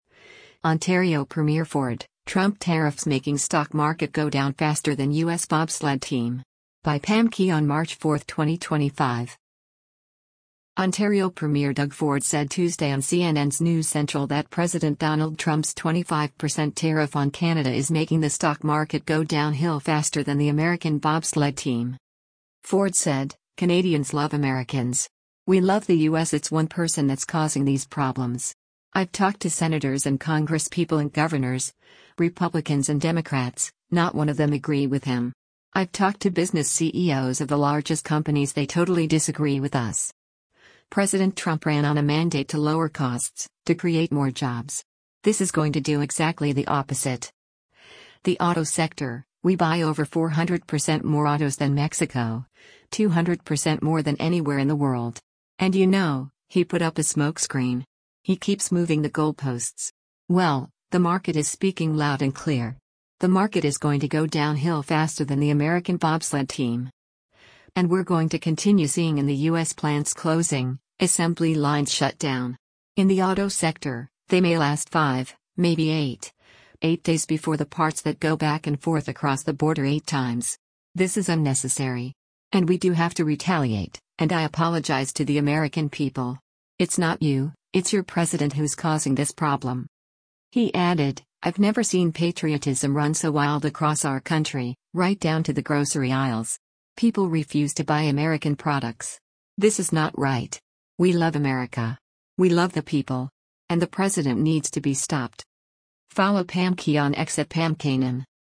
Ontario Premier Doug Ford said Tuesday on CNN’s “News Central” that President Donald Trump’s 25% tariff on Canada is making the stock market go “downhill faster than the American bobsled team.”